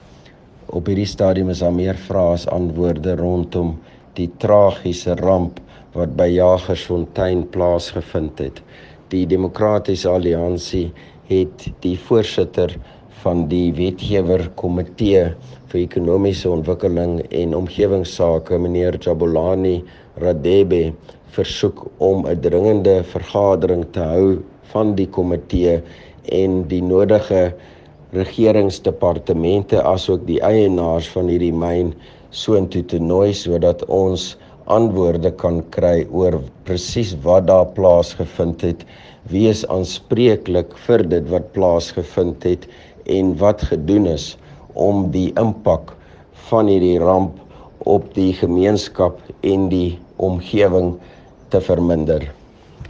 Afrikaans soundbites by Roy Jankielsohn MPL with images here, here, here, here and here.